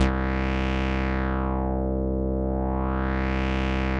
Index of /90_sSampleCDs/Trance_Explosion_Vol1/Instrument Multi-samples/Wasp Dark Lead
C2_wasp_dark_lead.wav